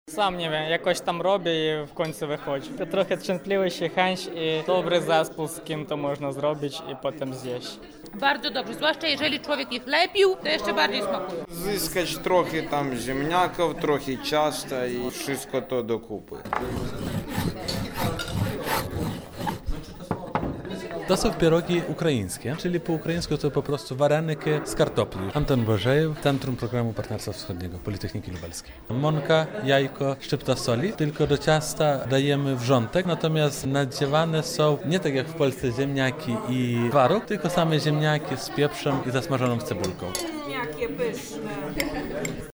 Wczoraj na Politechnice Lubelskiej studenci z Ukrainy pokazywali, jak przyrządzić to danie.
Na miejscu była nasza reporterka: